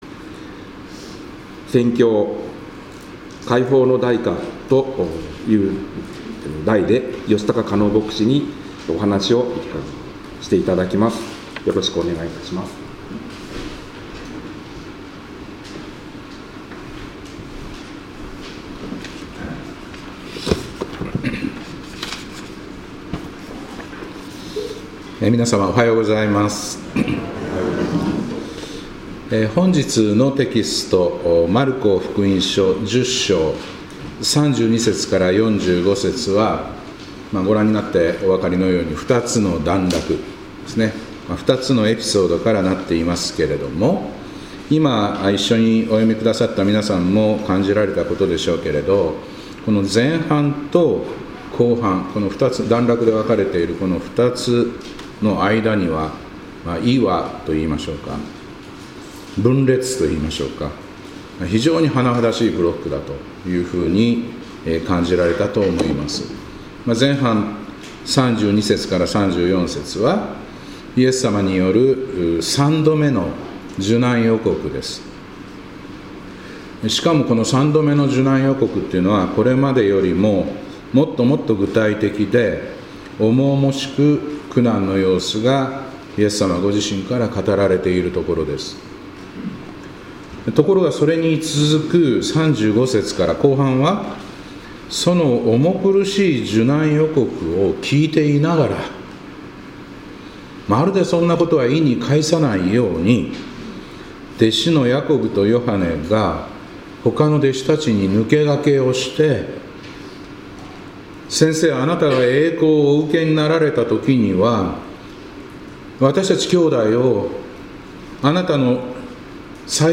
2026年3月1日礼拝「解放の代価」